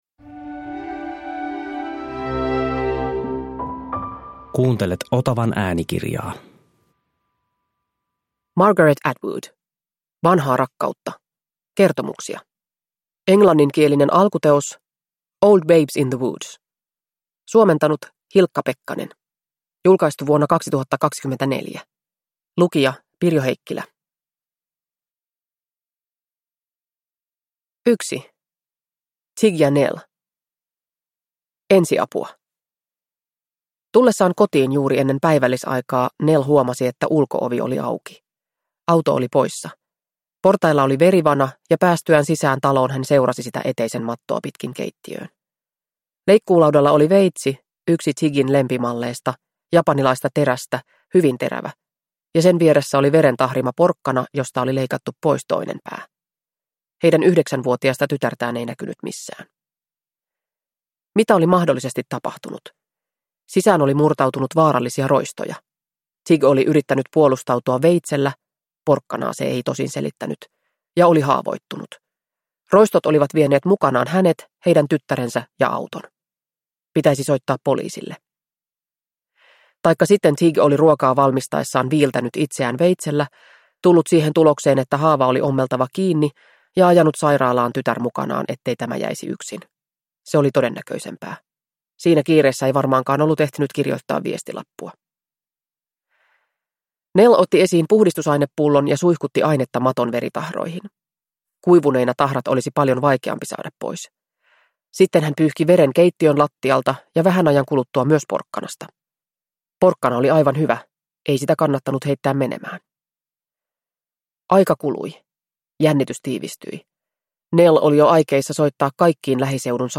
Vanhaa rakkautta – Ljudbok